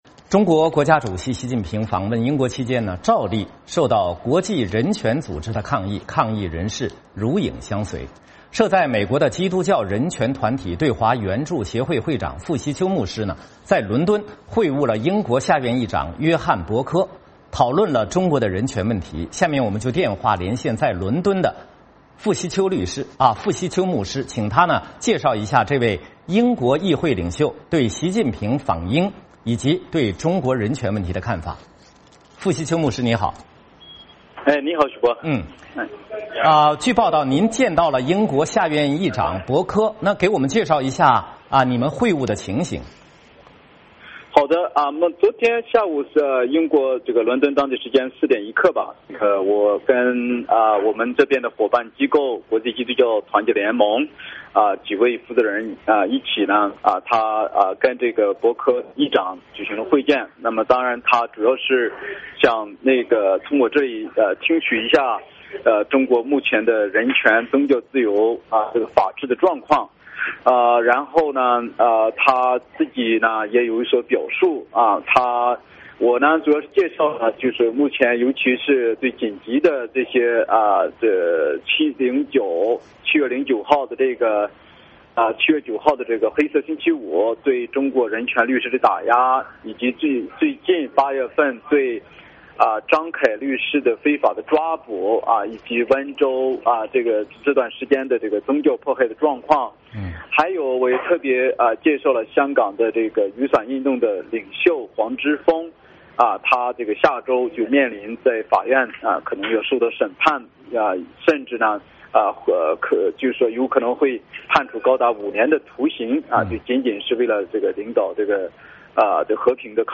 设在美国的基督教人权团体对华援助协会会长傅希秋牧师在伦敦会晤了英国下院议长约翰•伯科，讨论了中国的人权问题。我们电话连线在伦敦的傅希秋律师，请他介绍这位英国议会领袖对习近平访英以及中国人权问题的看法。